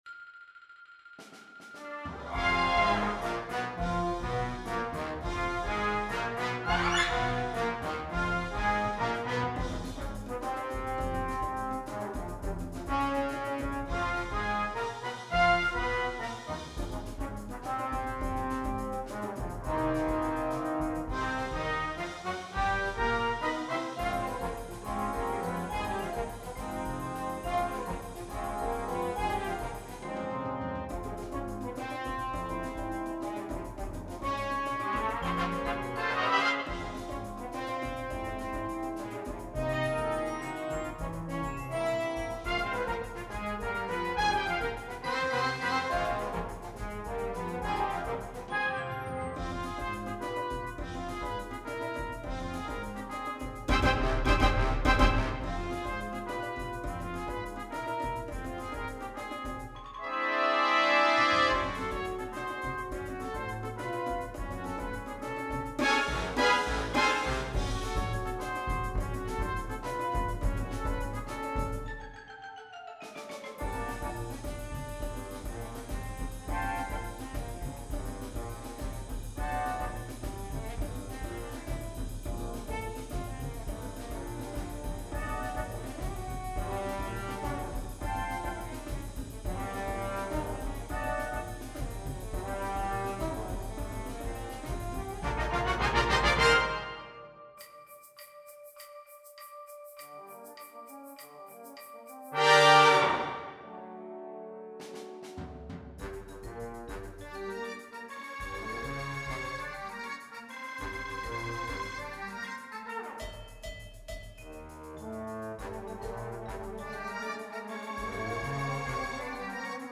cet arrangement des crédits de fin du film pour Brass-Band